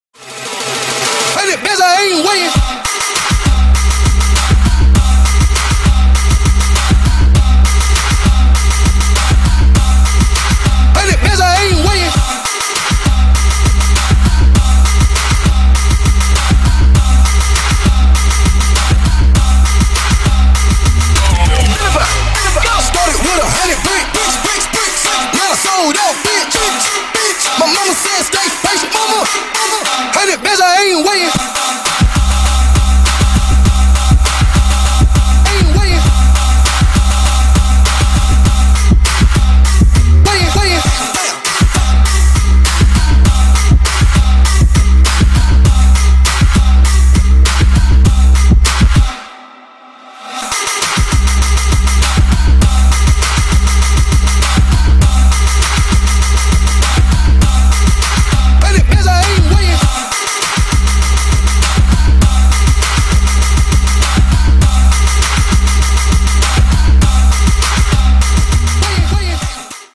• Качество: 128, Stereo
Trap